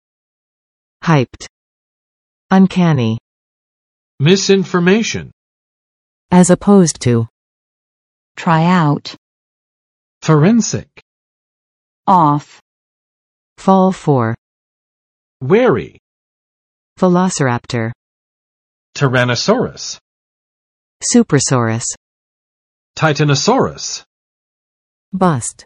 hyped [haɪpt] adj. very excited and energetic